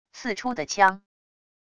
刺出的枪wav音频